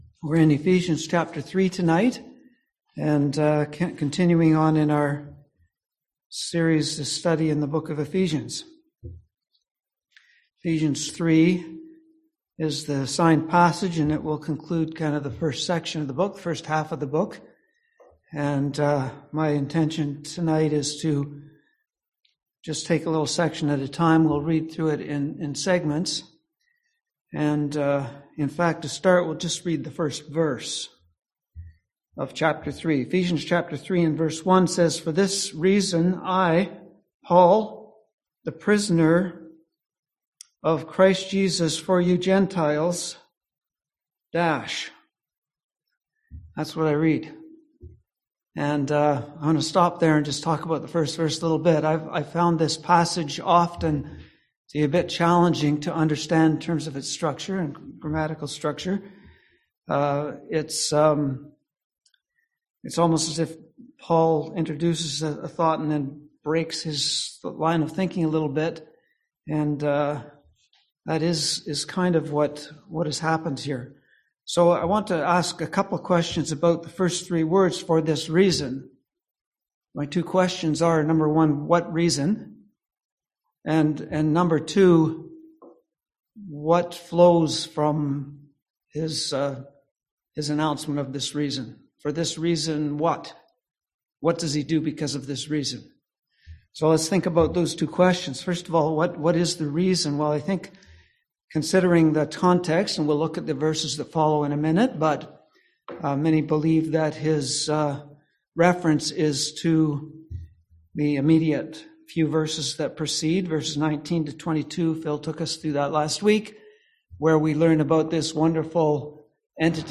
Series: Ephesians 2022 Passage: Ephesians 3:1-21 Service Type: Seminar